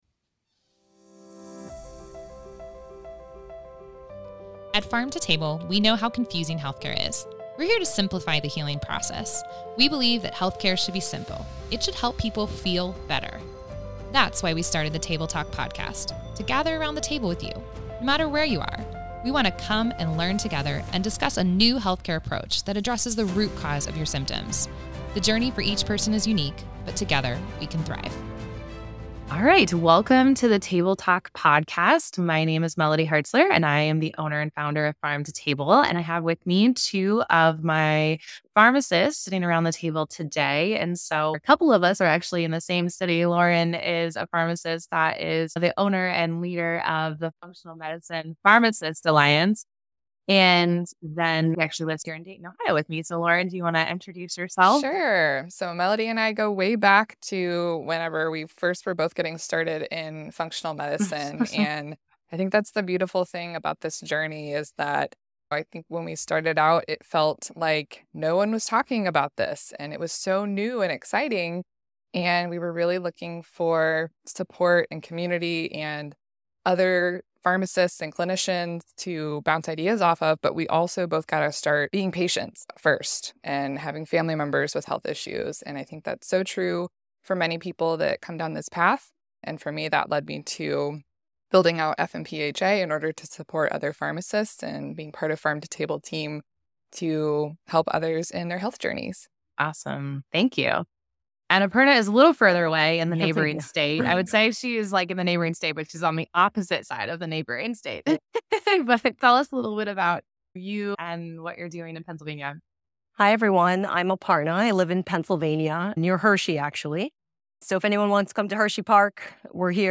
In this episode, two of our pharmacists discuss how they were led to functional medicine through their own health journeys.